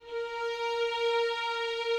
Added more instrument wavs
strings_058.wav